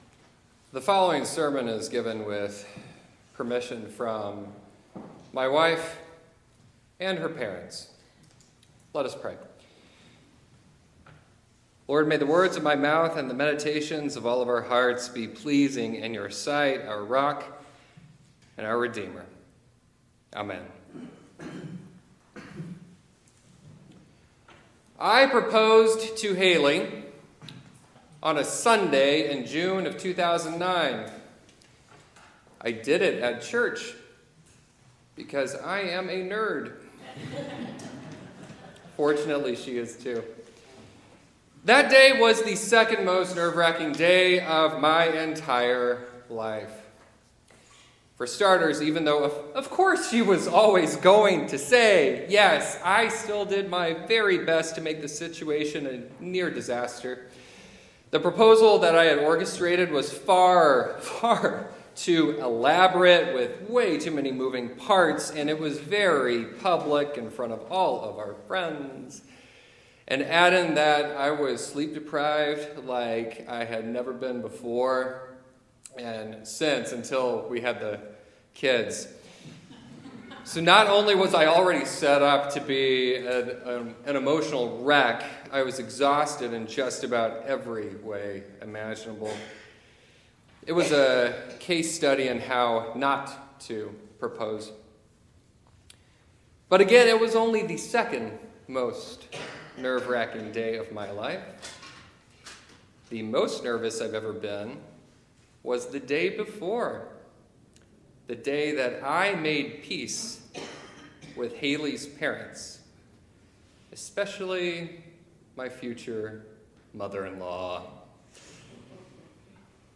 Gospel Lesson